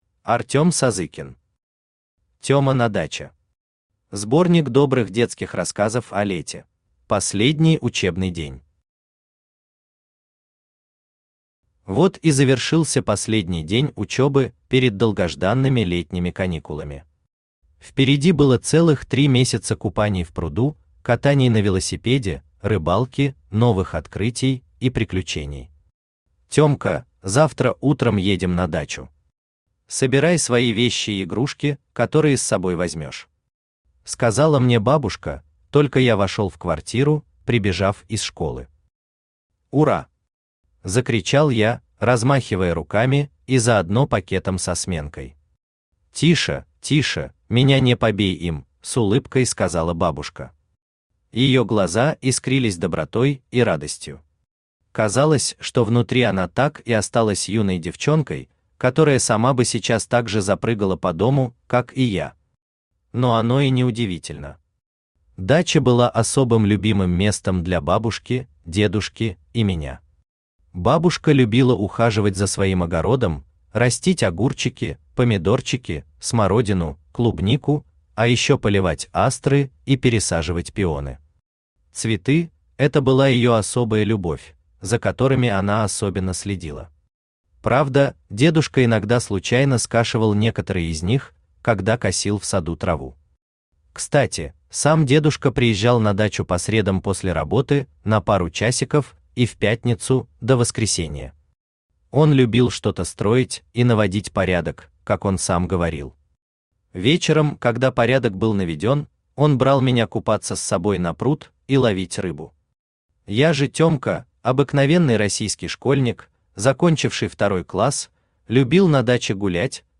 Аудиокнига Тёма на даче. Сборник добрых детских рассказов о лете | Библиотека аудиокниг
Сборник добрых детских рассказов о лете Автор Артем Сазыкин Читает аудиокнигу Авточтец ЛитРес.